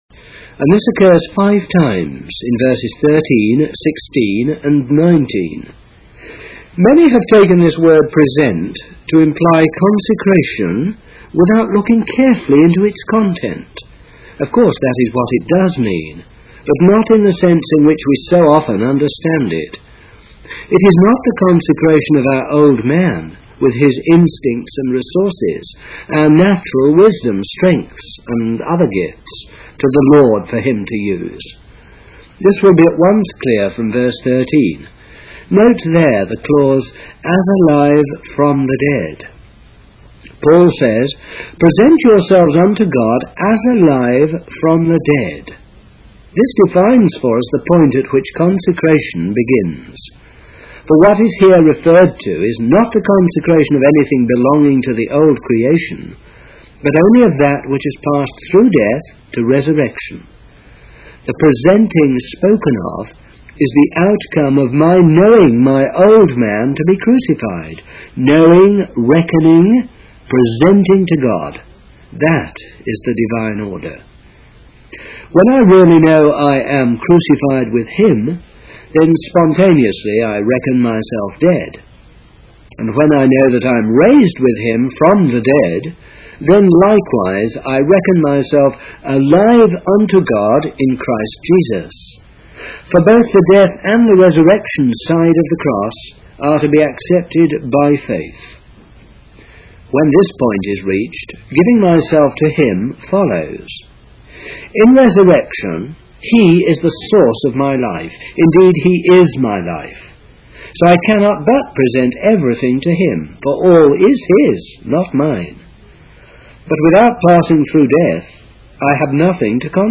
Eternal Purpose (Reading) by Watchman Nee | SermonIndex